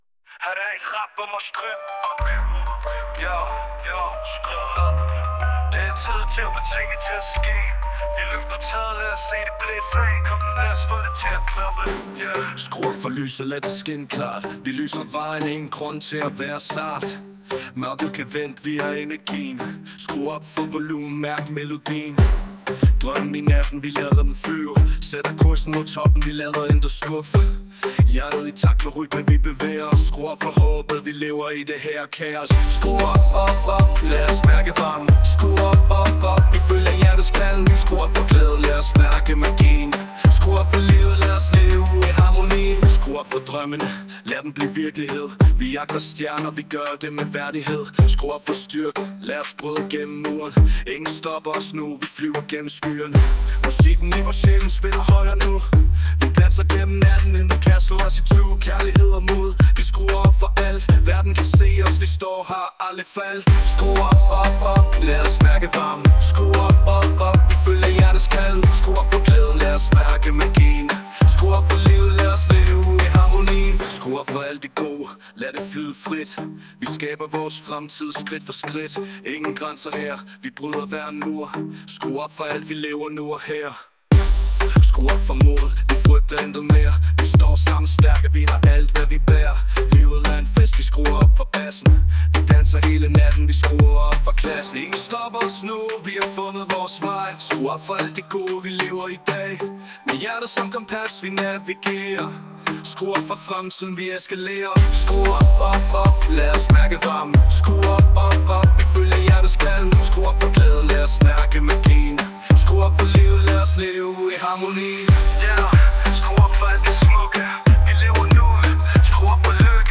Det er et nummer der hedder skru op, og er i kategorien rap.